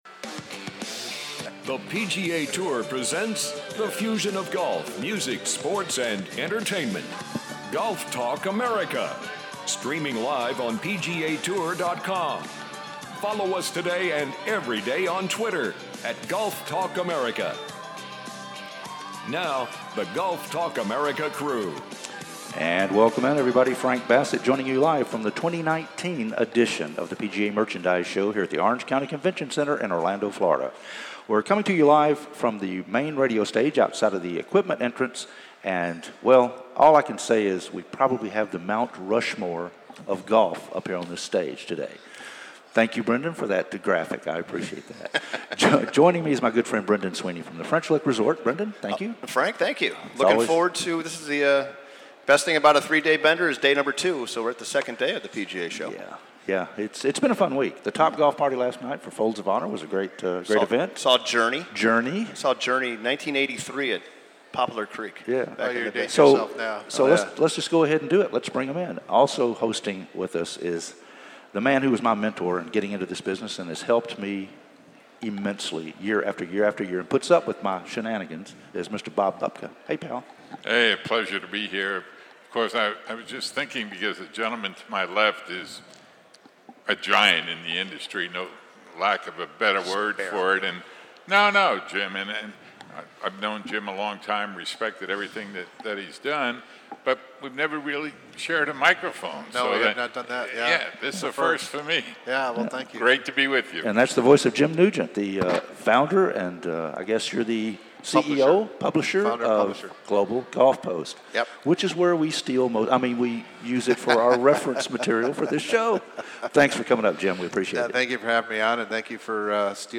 The PGA Show "LIVE" with Mike Whan